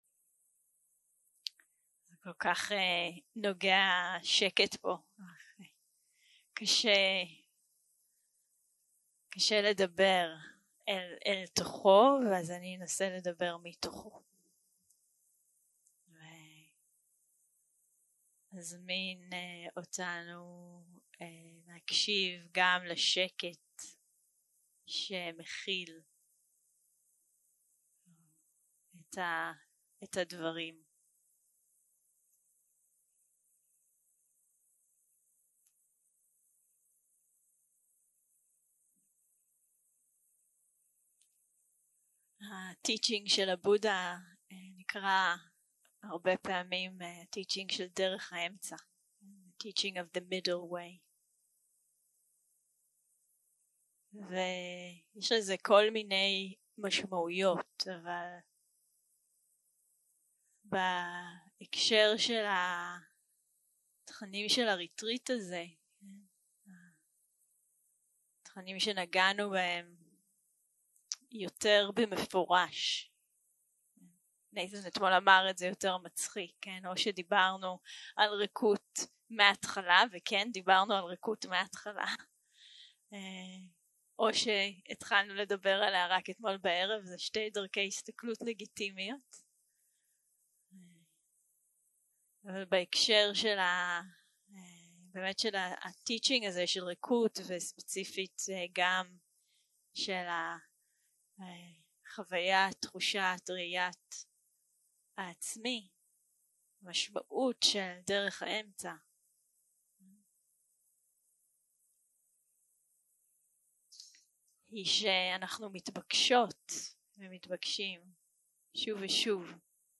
יום 6 - הקלטה 22 - ערב - שיחת דהרמה.
סוג ההקלטה: שיחות דהרמה